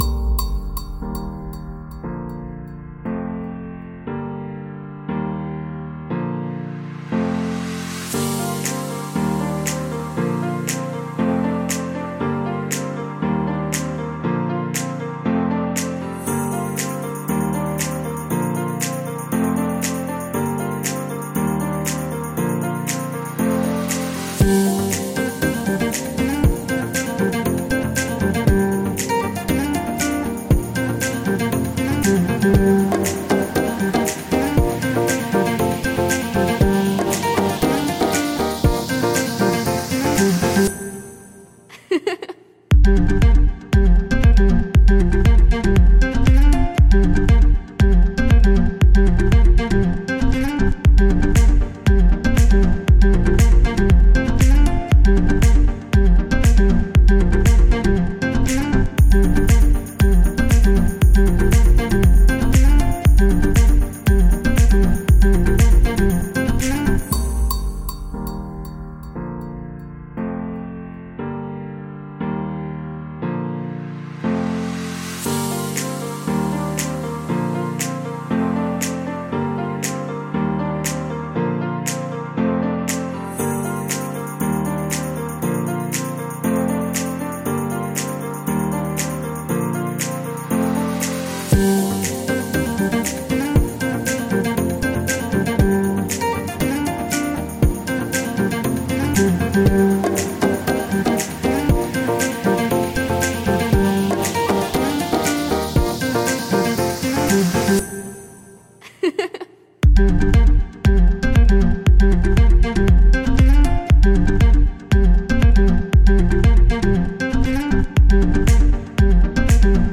radiomarelamaddalena / STRUMENTALE / SAX /